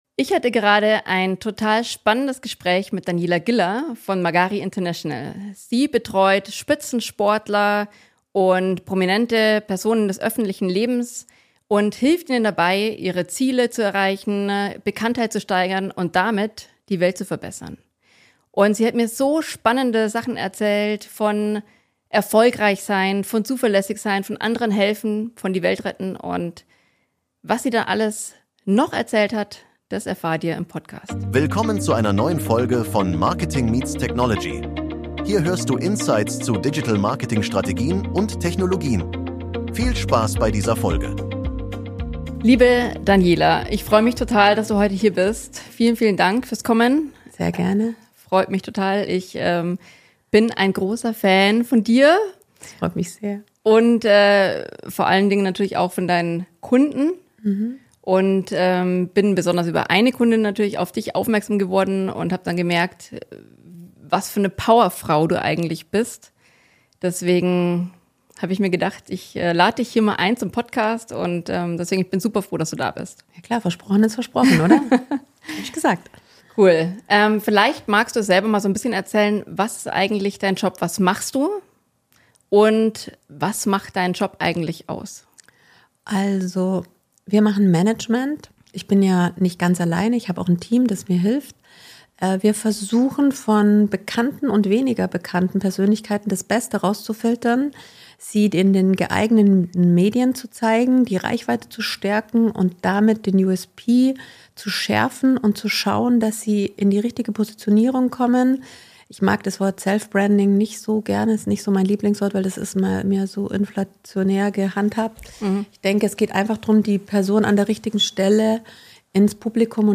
Wie geht Positionierung richtig? | Interview